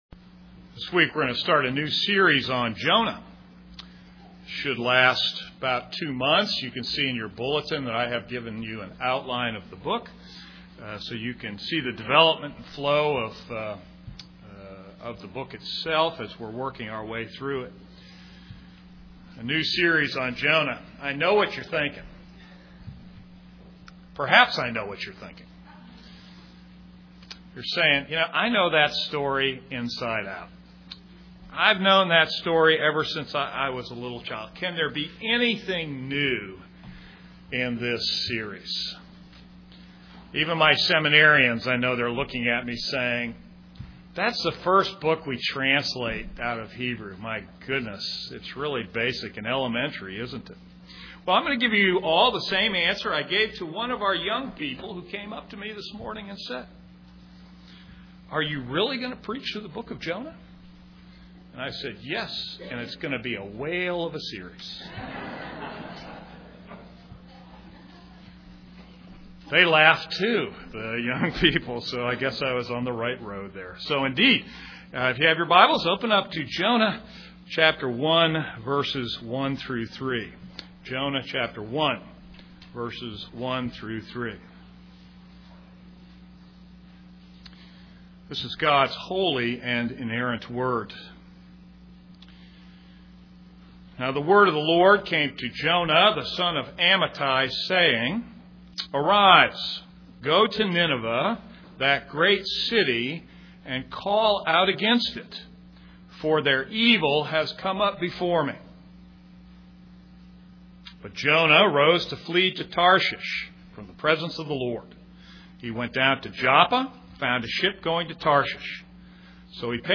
This is a sermon on Jonah 1:1-3.